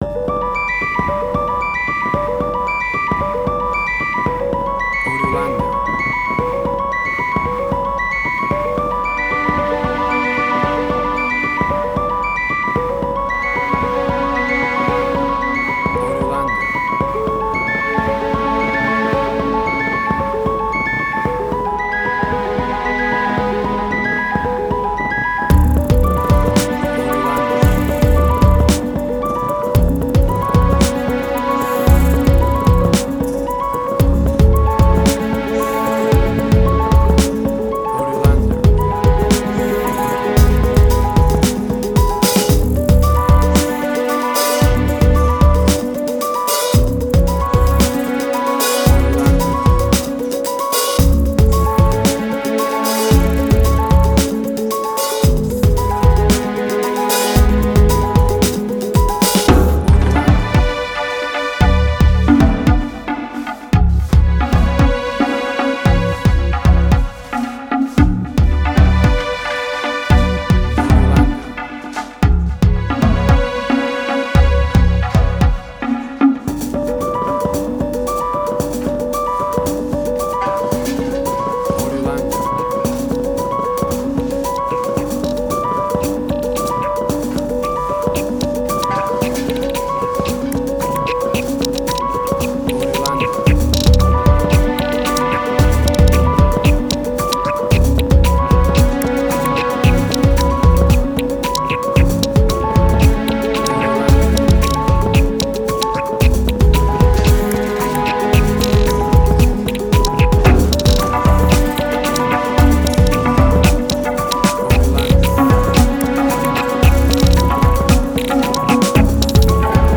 IDM, Glitch.
Tempo (BPM): 113